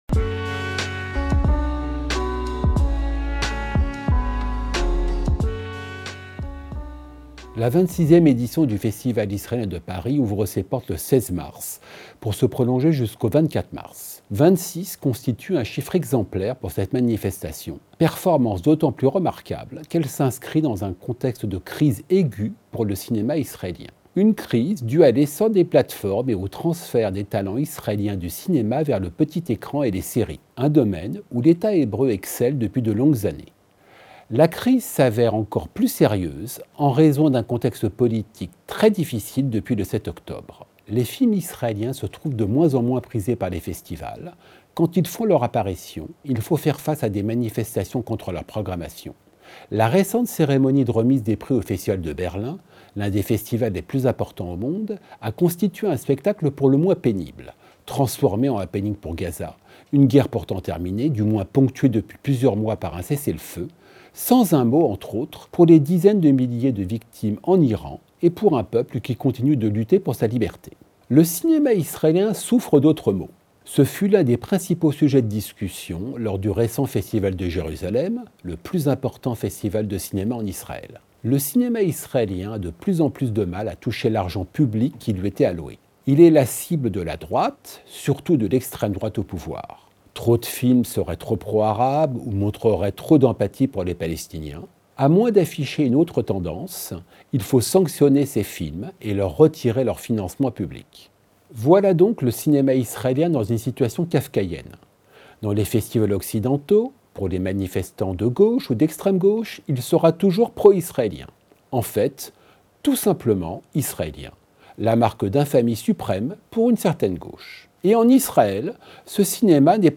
Chronique
journaliste